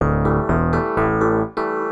SYNTH005_VOCAL_125_A_SC3(L).wav